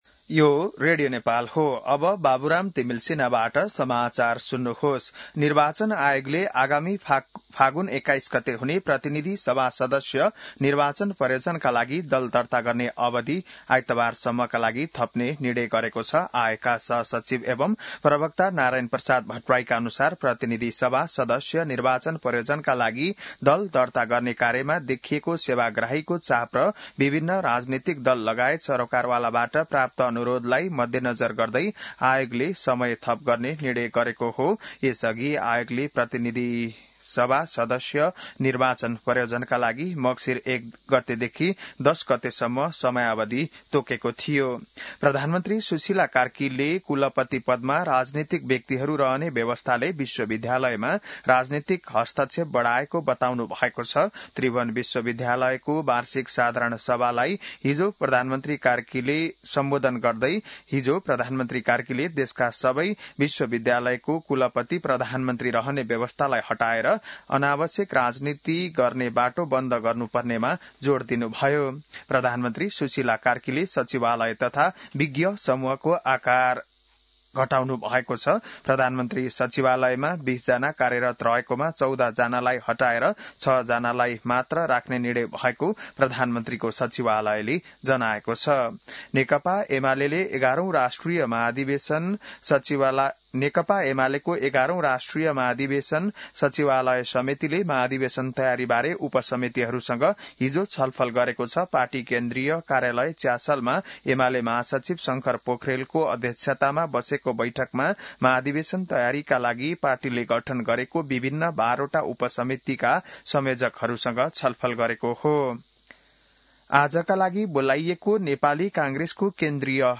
बिहान १० बजेको नेपाली समाचार : ११ मंसिर , २०८२